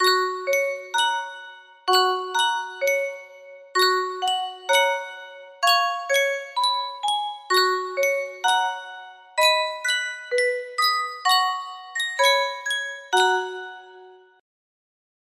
Sankyo Music Box - Goodnight Ladies S= music box melody
Full range 60